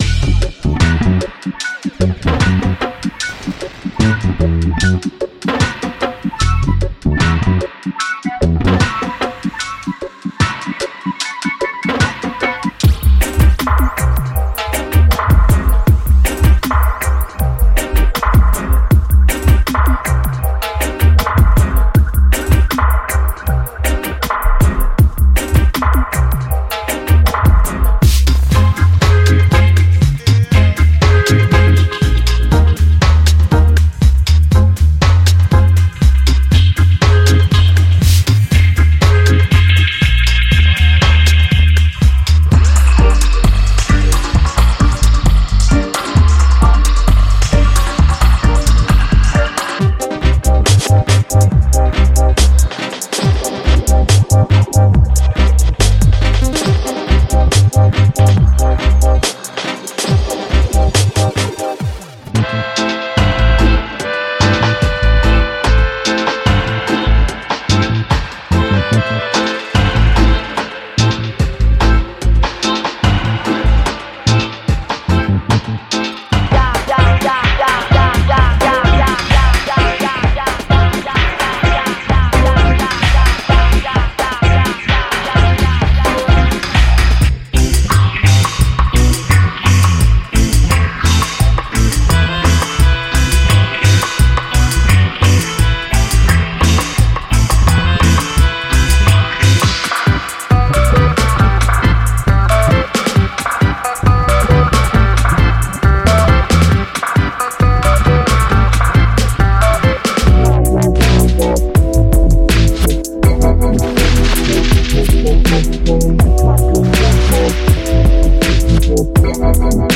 クラシックおよびヴィンテージのエレクトリックキー、多彩なパッド、単音、スカンク、リズムグルーヴなどを収録しています。
コード／スタブ／スカンク／パッド／リフ
オルガン
ピアノ
エレクトリックピアノ
クラビネット
メロディカ
デモサウンドはコチラ↓
Genre:Reggae
Tempo Range: 65-175 BPM